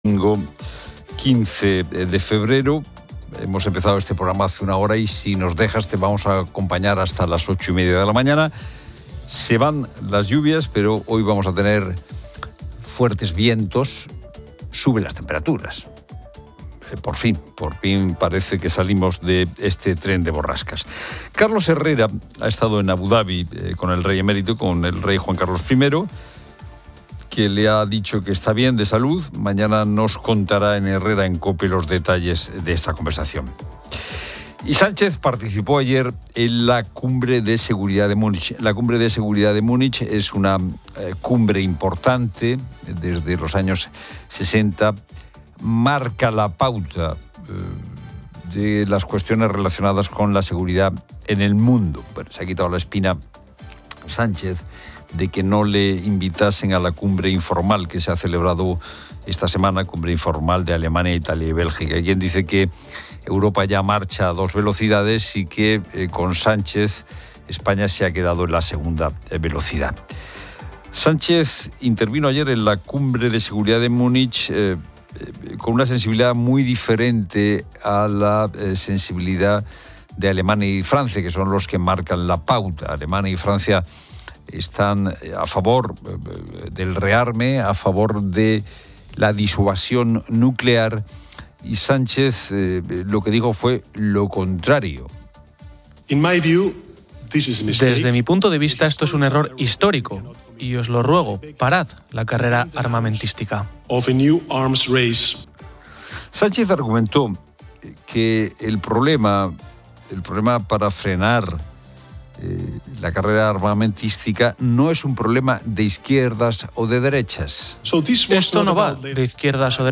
Carlos Herrera entrevista al rey Juan Carlos I en Abu Dhabi, quien afirma estar bien.